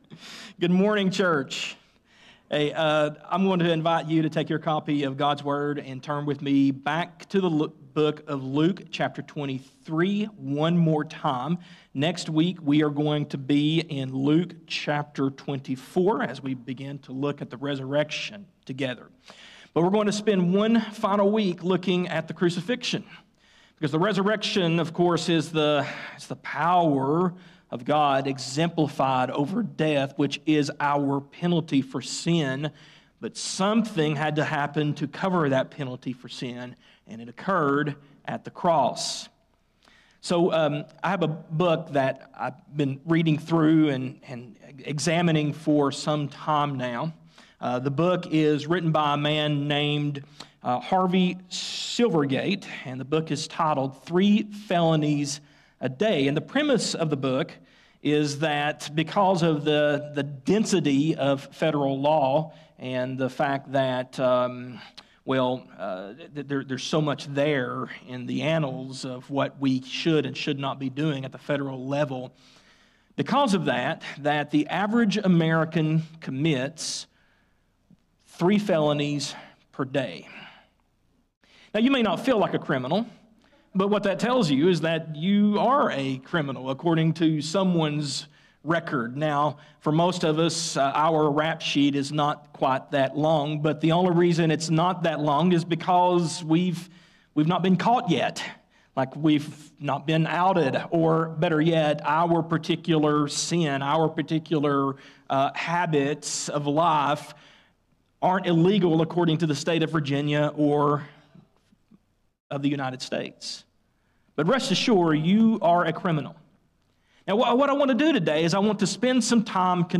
Sermons | Christiansburg Baptist Church | Christiansburg, VA